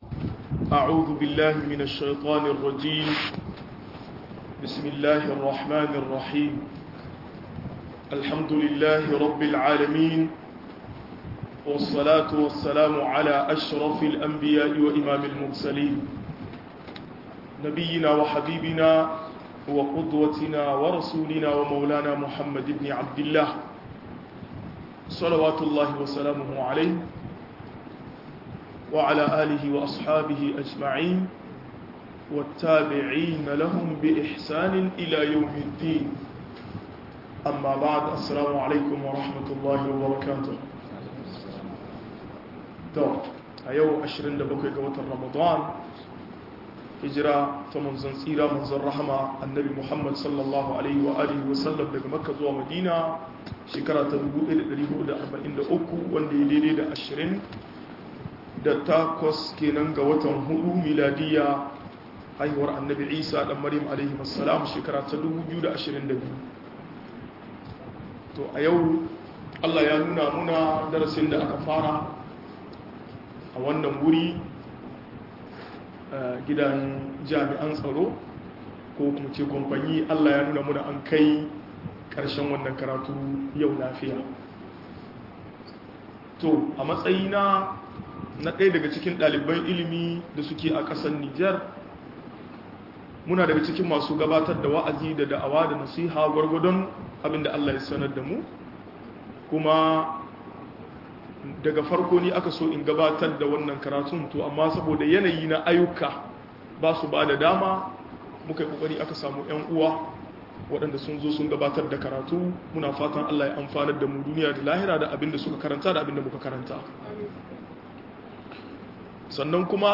Sanadiyyar halakar ɗan adam - MUHADARA